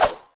player_dodge.wav